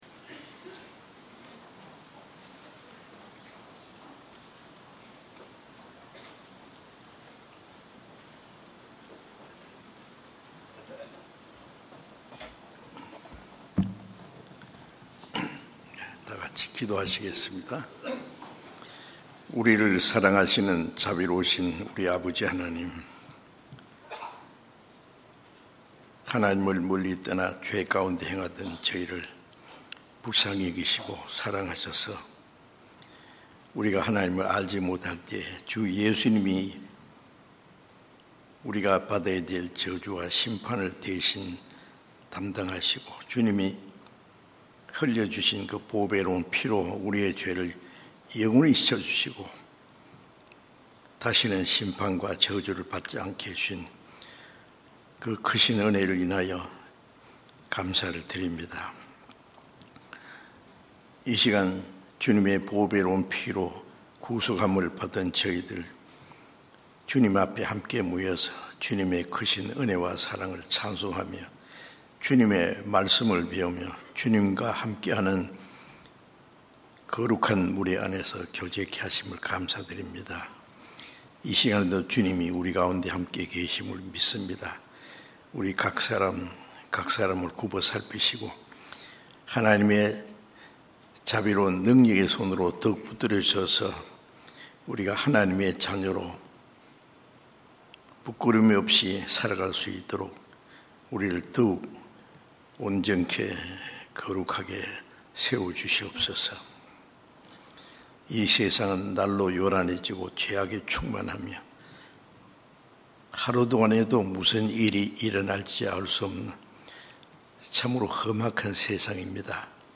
주일설교수요설교 (Audio)